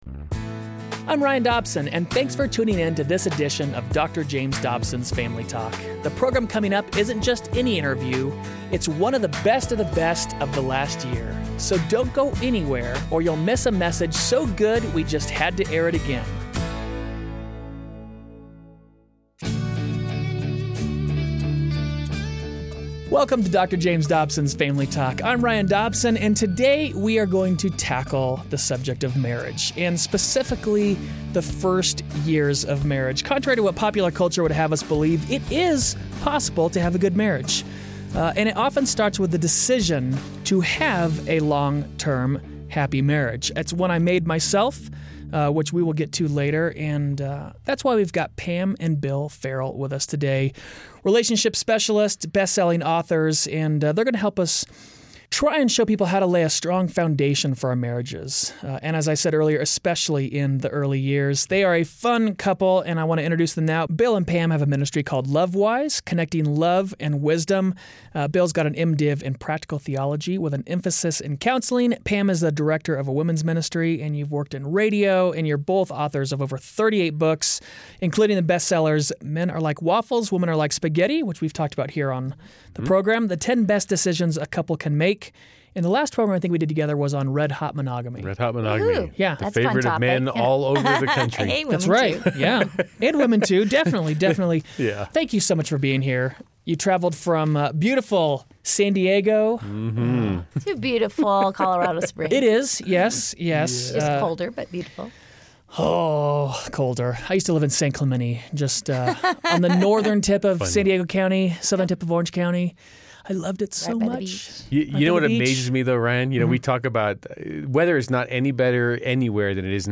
The key to long-lasting love is investing in your relationship at the beginning – in the first couple years – so you’ll enjoy your journey together for a lifetime. Hear from relationship experts who share the secrets that have made their love last for over 25 years! Whether you’re looking to get married or you already are—it’s a conversation you won’t want to miss.